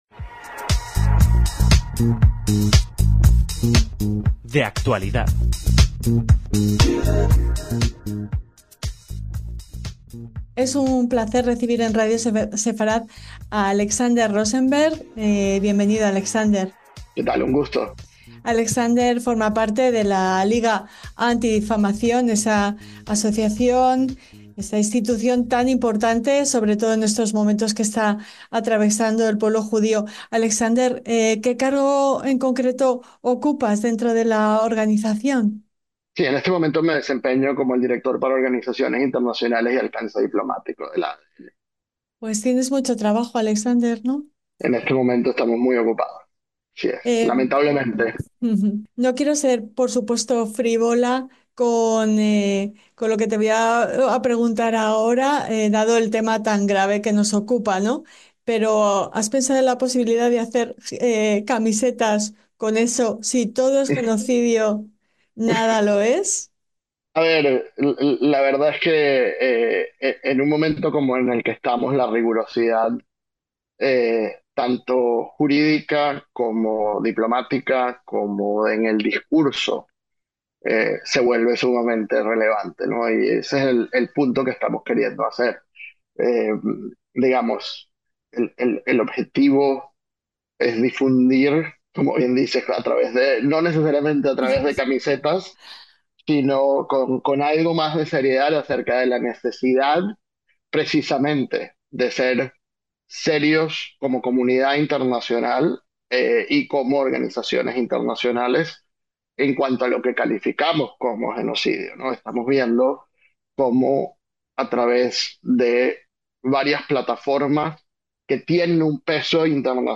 recorremos en esta entrevista